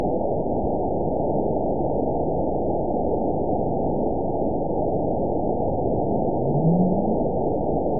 event 920341 date 03/17/24 time 21:04:40 GMT (1 year, 1 month ago) score 9.57 location TSS-AB02 detected by nrw target species NRW annotations +NRW Spectrogram: Frequency (kHz) vs. Time (s) audio not available .wav